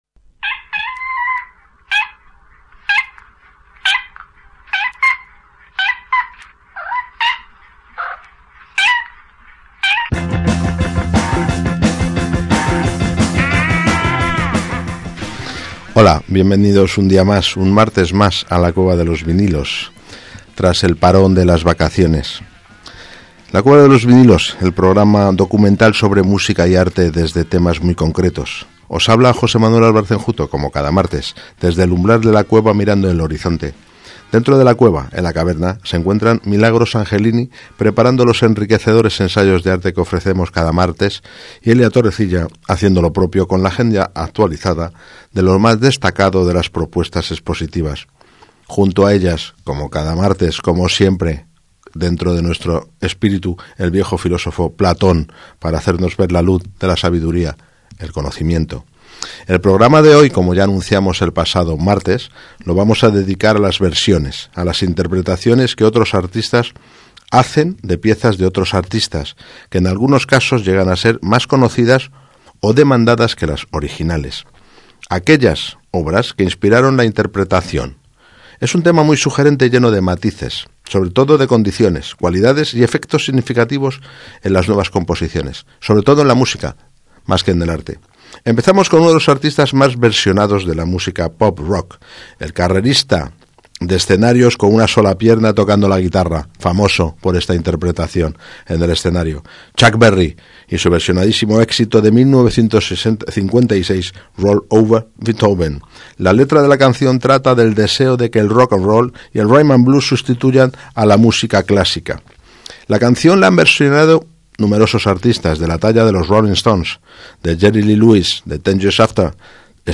Hoy en La Cueva… un programa dedicado a versiones, interpretaciones de artistas de temas de otros artistas.